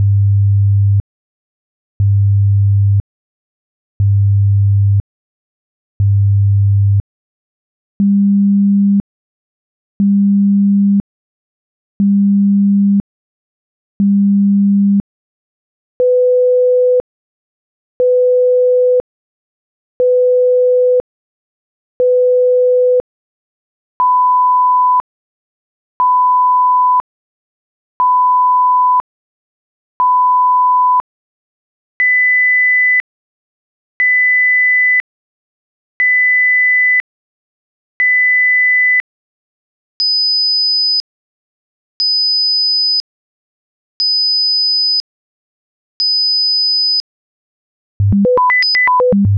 Atlas - STest1-Pitch-Right-100,200,500,1000,2000,5000.flac